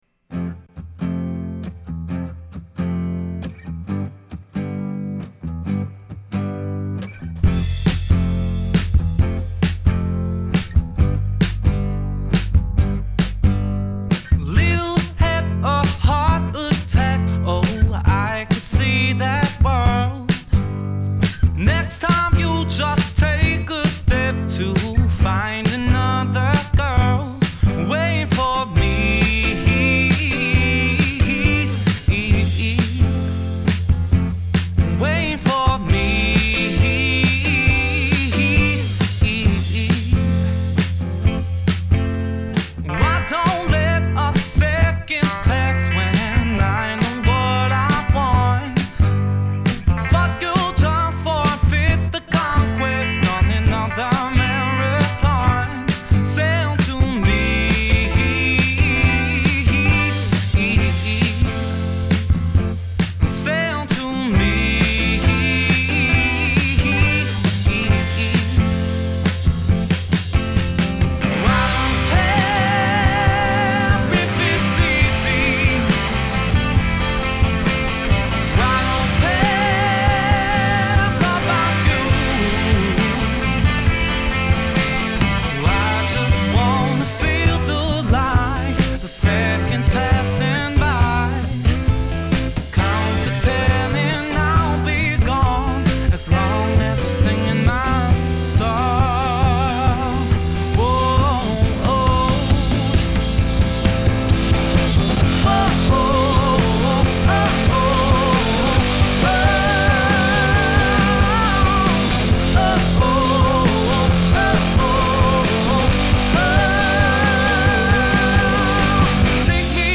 Genre: Rock 'n' Roll, Soul